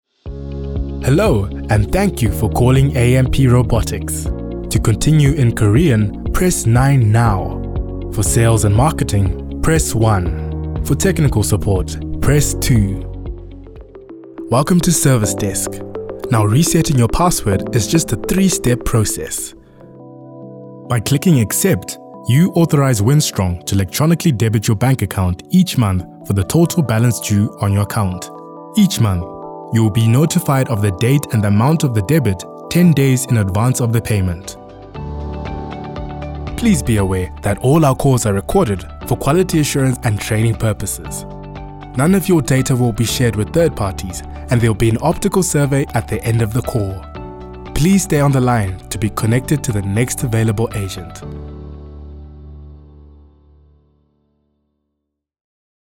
0328IVR_Demo.mp3